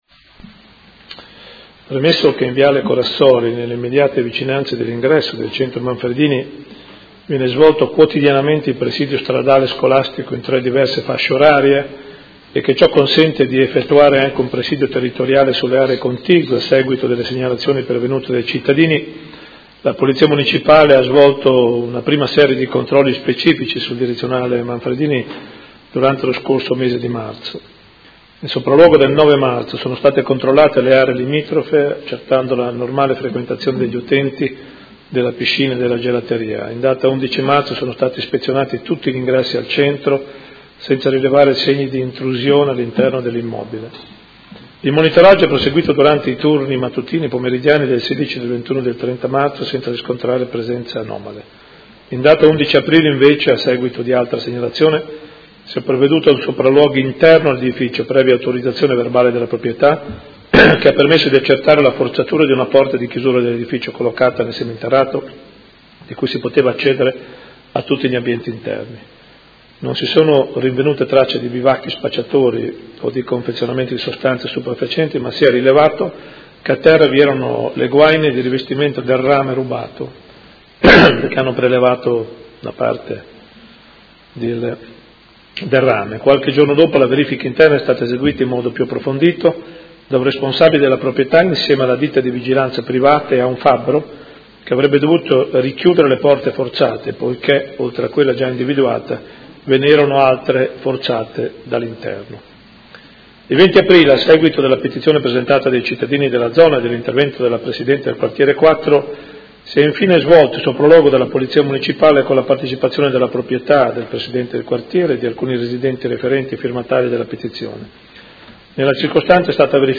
Seduta del 28/04/2016. Interrogazione della Consigliera Pacchioni (P.D.) avente per oggetto: Centro Direzionale Manfredini. Risponde il sindaco